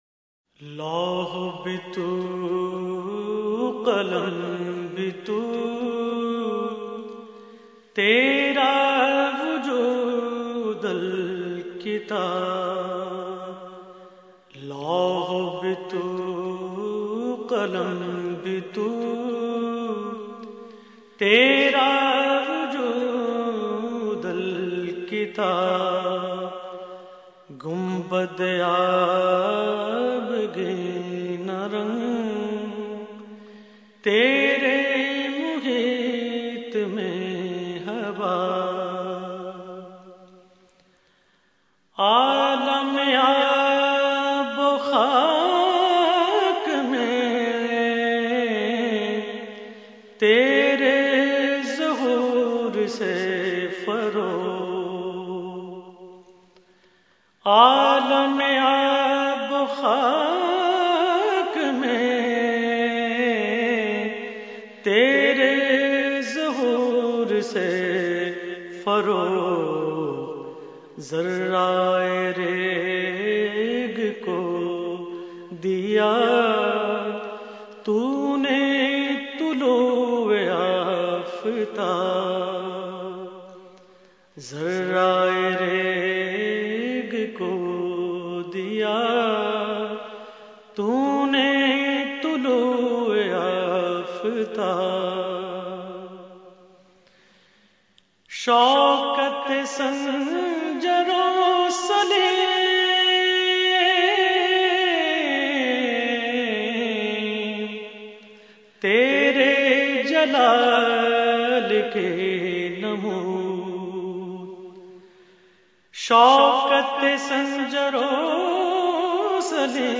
Category : Naat | Language : Urdu